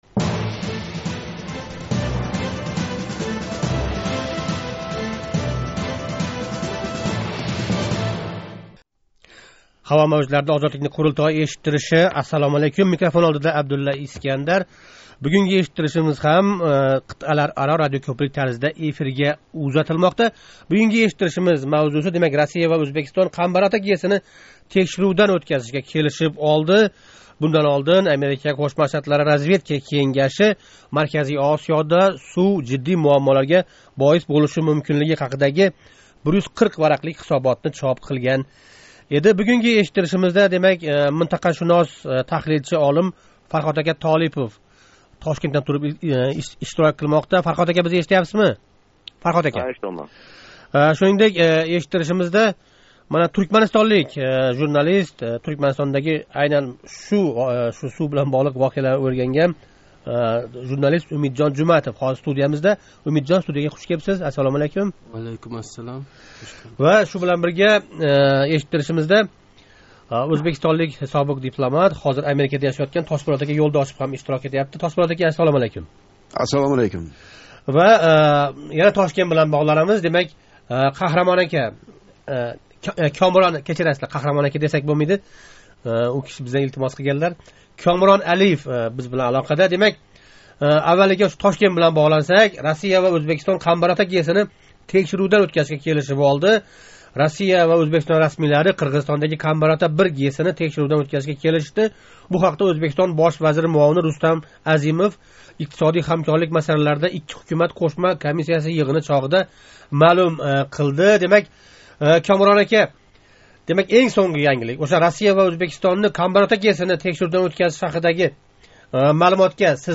“Қурултой” эшиттиришида Марказий Осиëда сув муаммоларини муҳокама қилган суҳбатдошларнинг муштарак фикри ана шундай бўлди. Пайшанба куни Россия ва Ўзбекистон Қамбарота ГЭСини текширувдан ўтказишга келишгани боис “Қурултой”да айни мавзуни муҳокама қилдик.